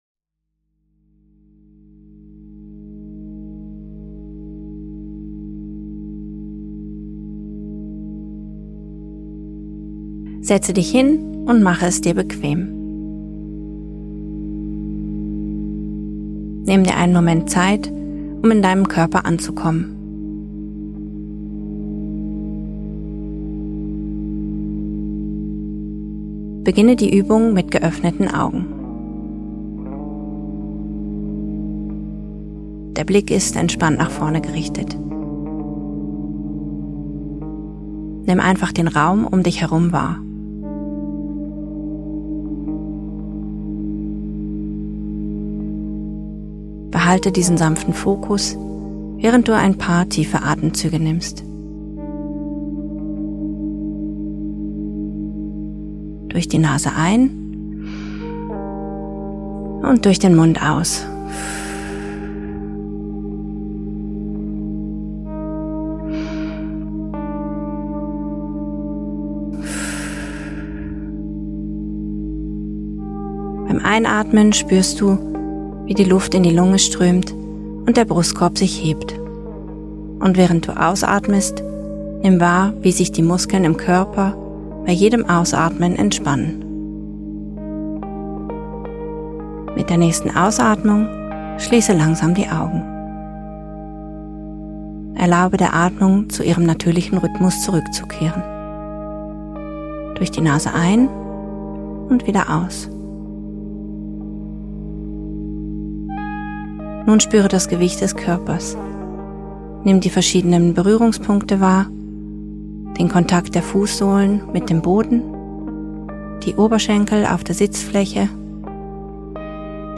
Meditation bietet den optimalen geleiteten Einstieg und hilft beim